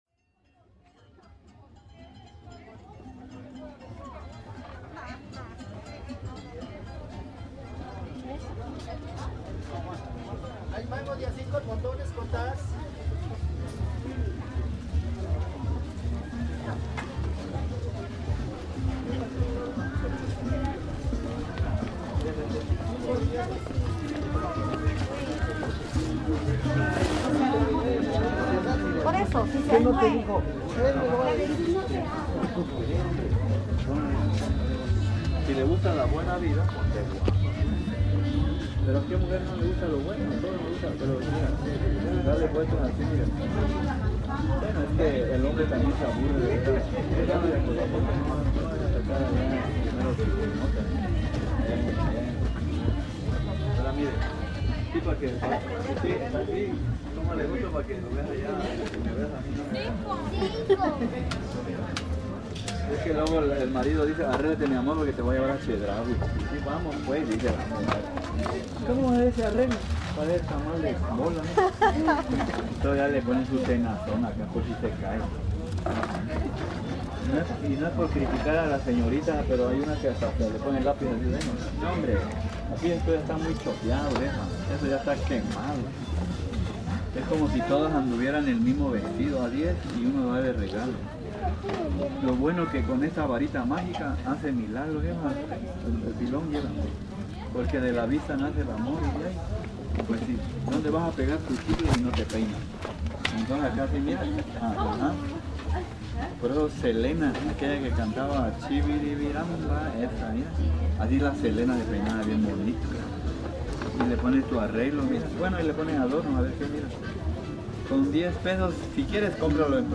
Imaginen estas y otras preguntas que se harían al escuchar al merolico que rompe con sus ocurrencias la cotidianidad del mercado más grande y antiguo de San Cristóbal de Las Casas.